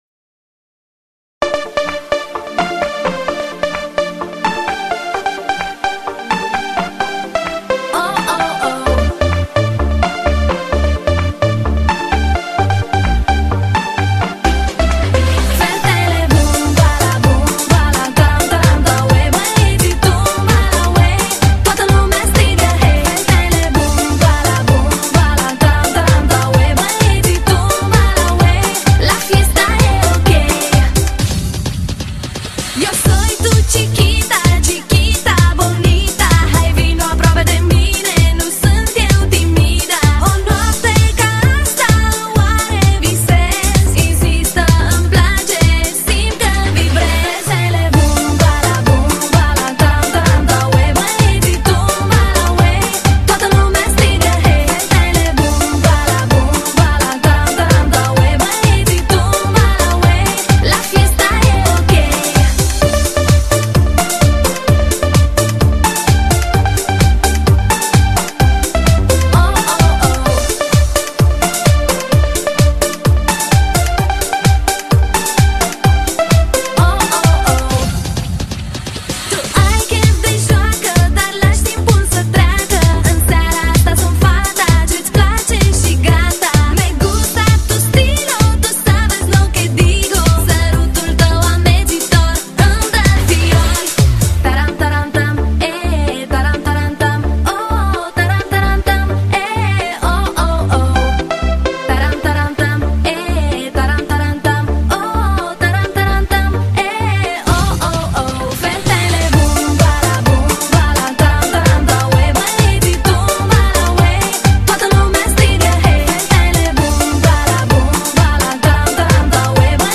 HI-FI品质保证，完美现场感，沸腾全球的舞曲，音乐狂潮，不可遏止的热浪，激情与速度齐飞扬。